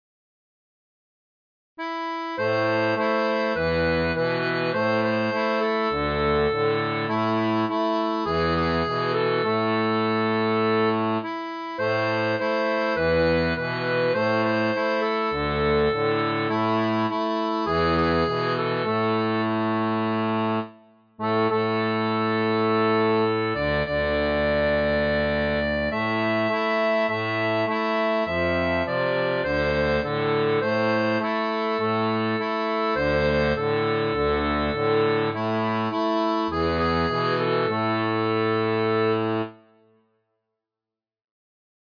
• La tablature en Am pour diato 2 rangs
• Un fichier audio basé sur la rythmique originale
Chanson française